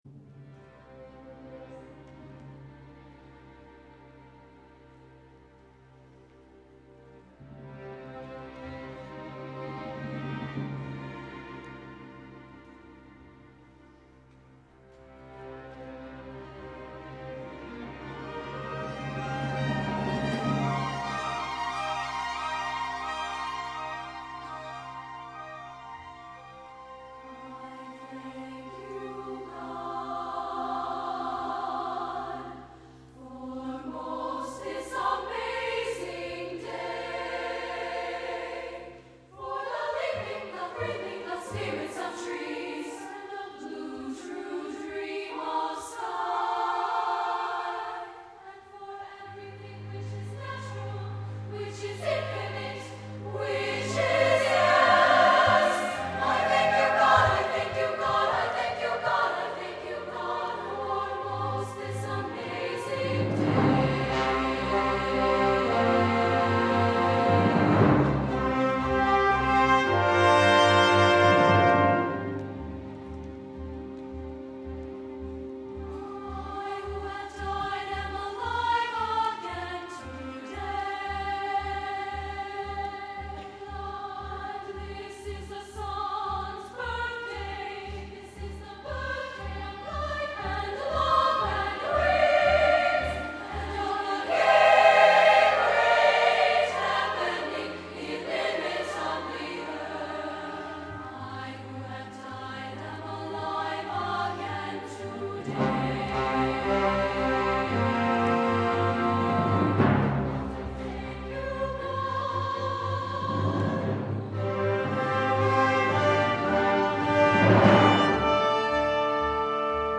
SSA and orchestra